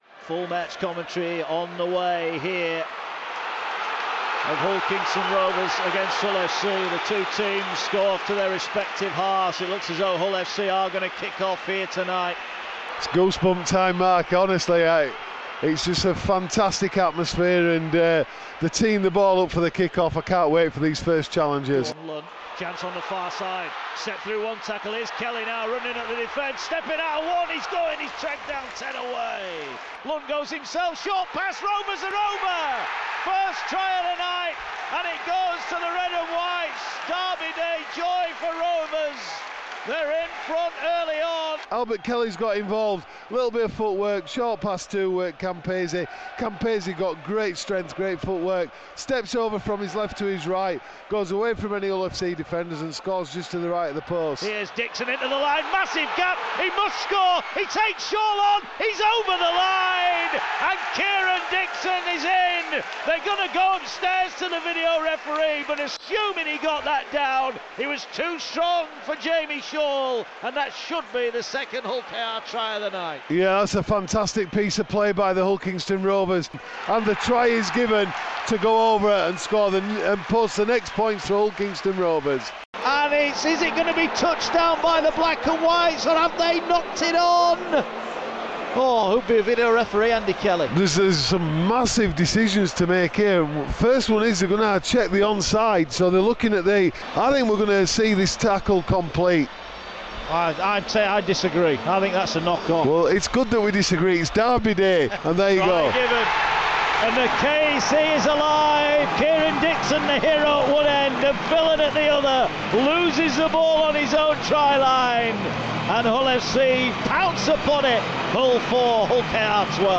Live match highlights of the 224th Hull derby live on Radio Yorkshire as the Rovers beat the Black & Whites by 6 points to 20 at the KC Stadium.